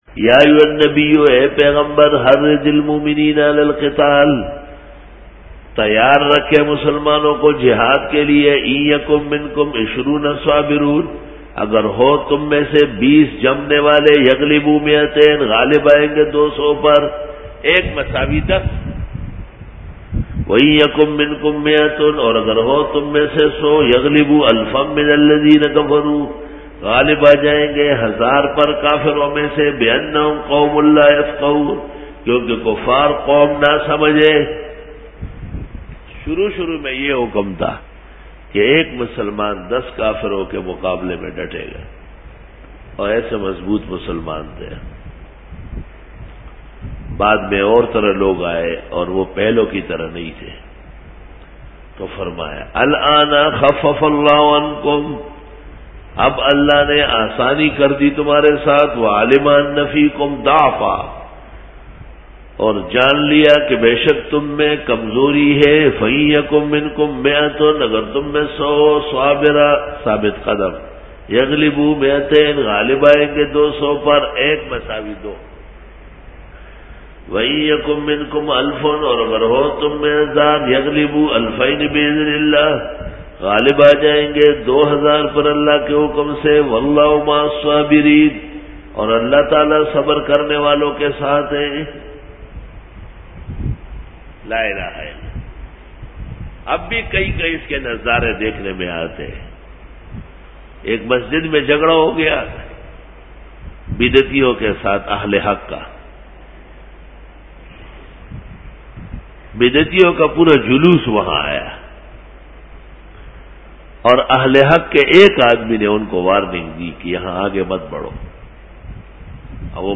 سورۃ الانفال رکوع-09 Bayan